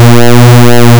Pulse Width Modulation
altsig = square(2 * np.pi * 120 * time, duty=sig2)
Anyone know why the sound appears to change in frequency over time?